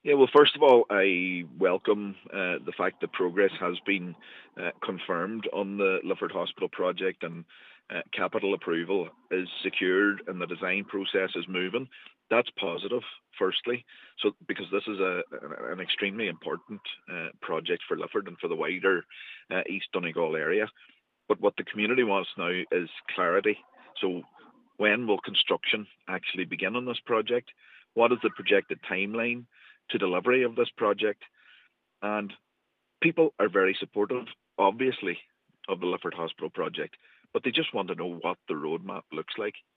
Local Councillor, Gary Doherty, has welcomed the confirmation but says there needs to be greater clarity on the project’s timeline: